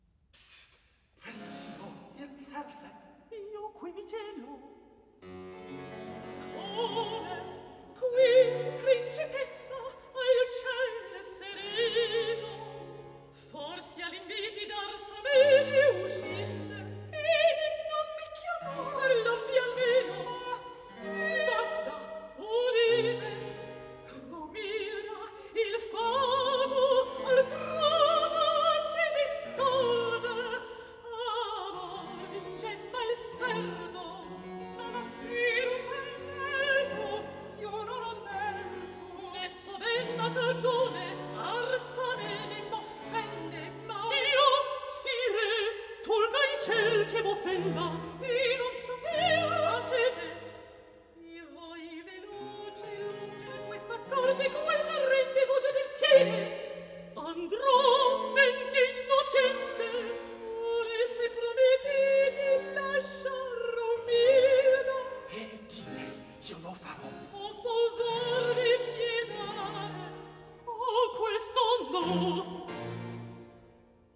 Act 1_ Recitativo_ _Presto